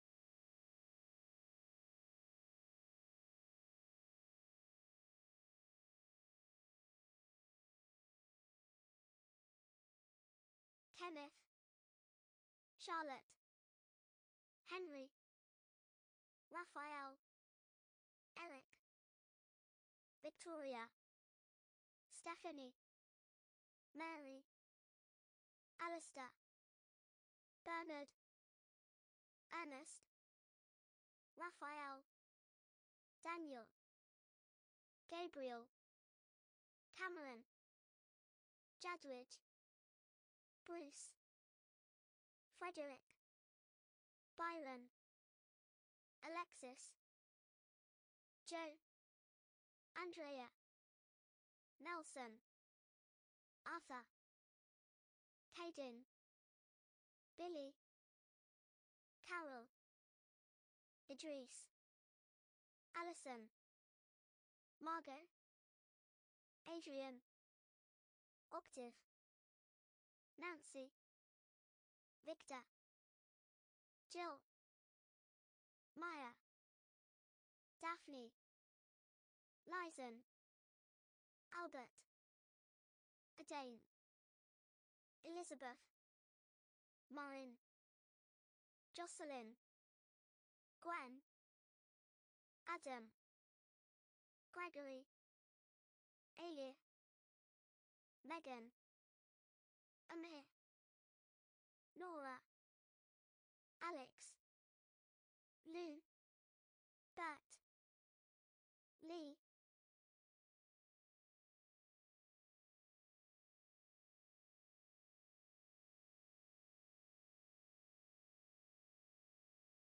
par une femme et un homme